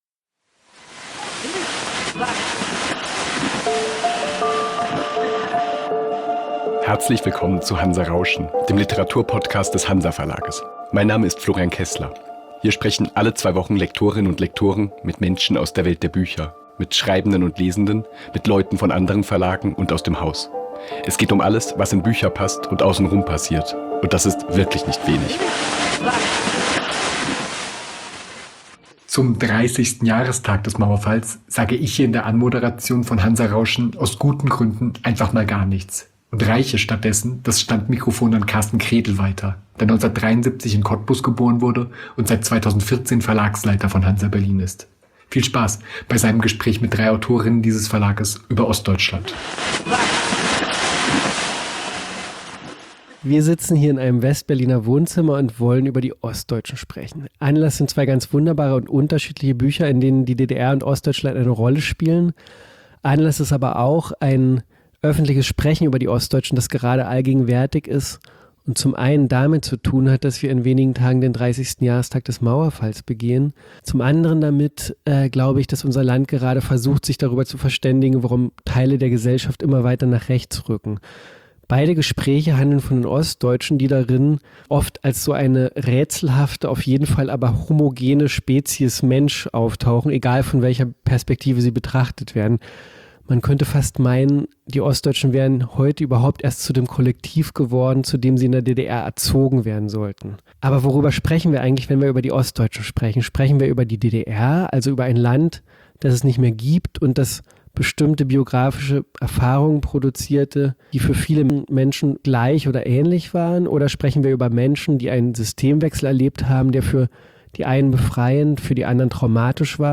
Zum 30. Jahrestag des Mauerfalls hinterfragt eine in Ostdeutschland aufgewachsene Runde in unserer 17. Podcastfolge diesen kleinen Satz nach allen Regeln der Kunst. "Die Ostdeutschen" als eine homogene Spezies gibt es nämlich gar nicht, wenn viele verschiedene individuelle Erfahrungen und Meinungen zusammenkommen.